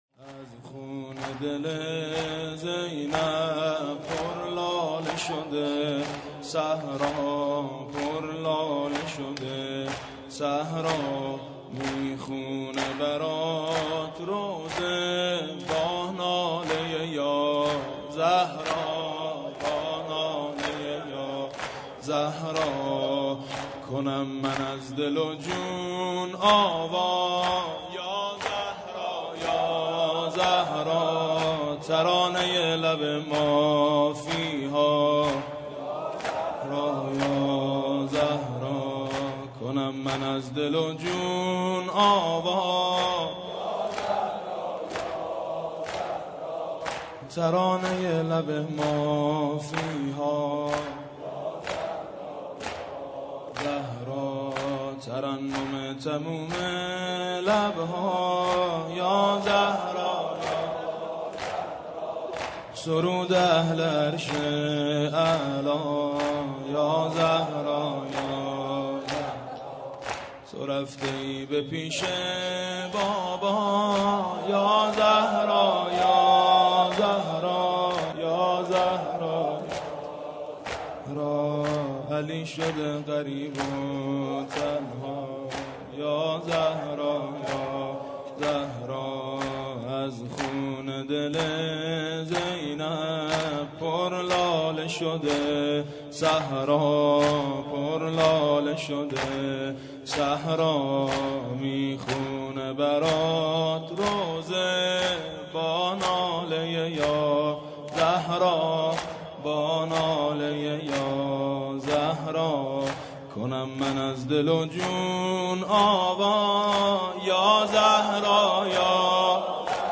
• Allah, God, Muslim, zeinab, mourning, Dirge, Islamic, ashora, Imam Hussein